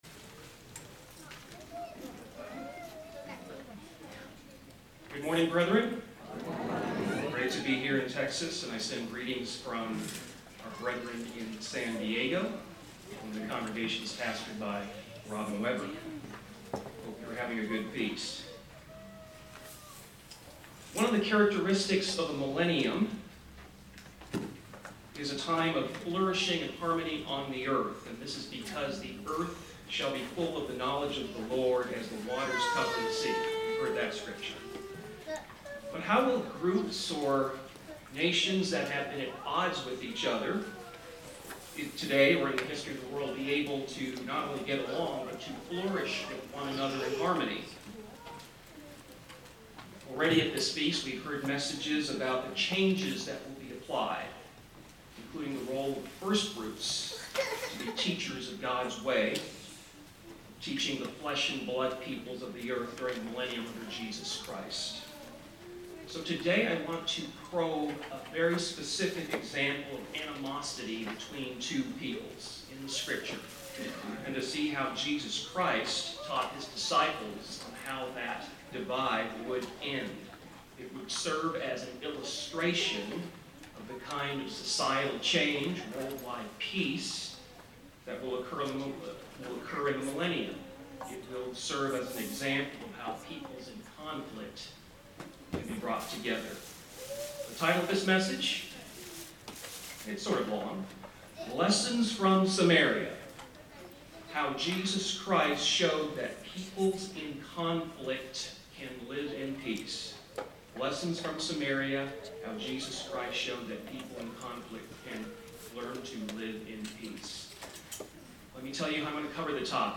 This sermon was given at the Bastrop, Texas 2022 Feast site.